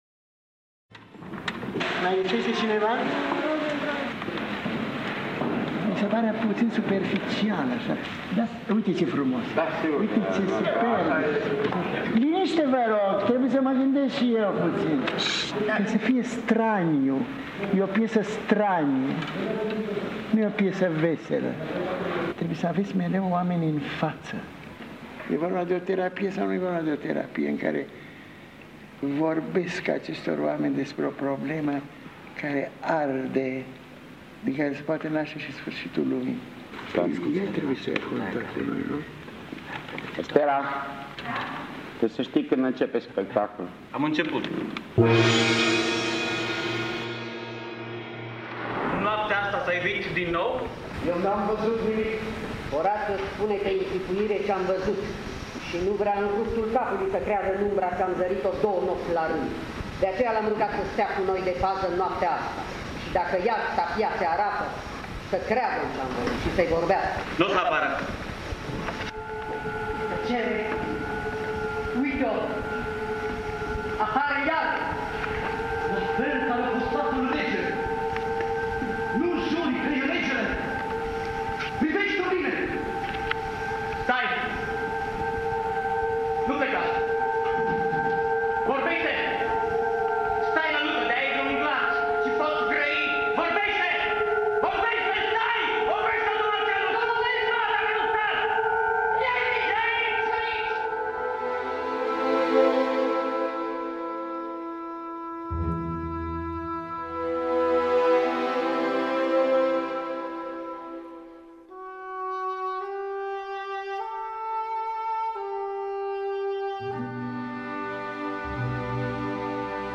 Spectacol de teatru-document bazat pe confesiunile regizorului Vlad Mugur. Cu participarea extraordinară a actriței Olga Tudorache.
Documente sonore din Fonoteca de aur a Societății Române de Radiodifuziune – mărturii ale profesoarei Alice Voinescu și ale lui Vlad Mugur.